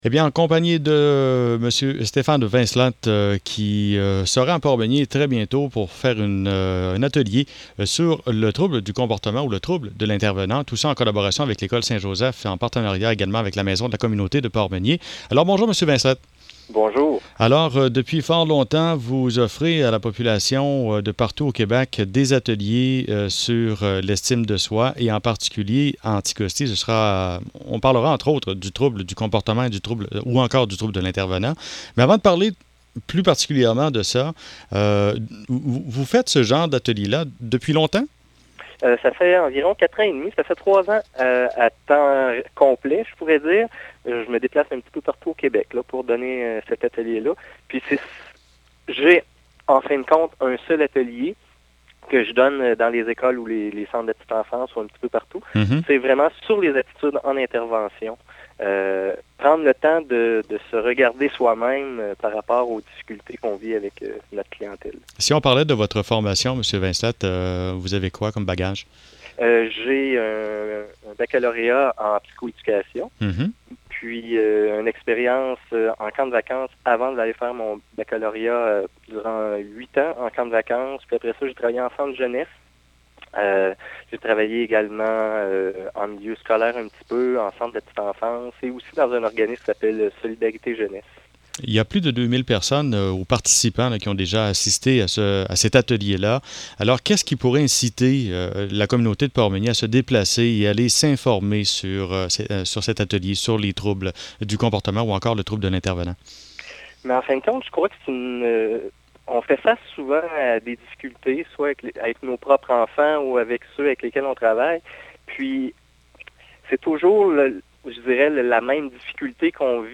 ENTREVUES À LA RADIO: